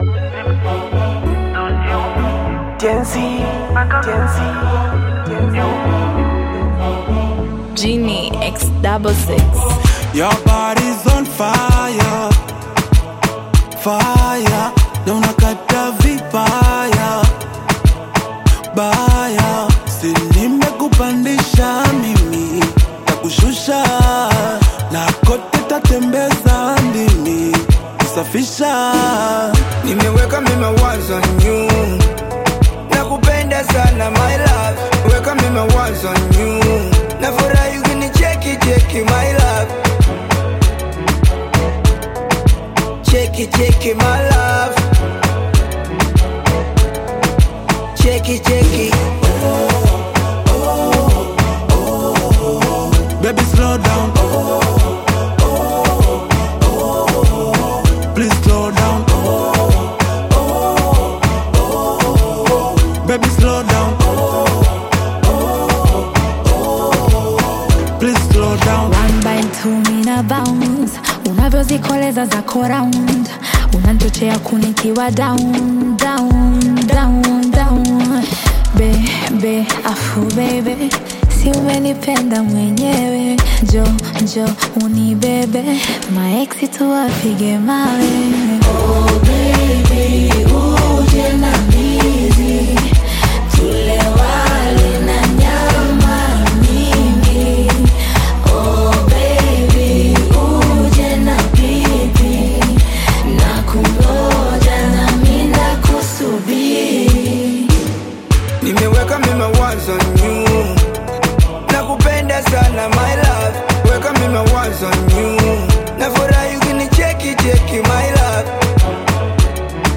captivating Afro-Bongo Flava single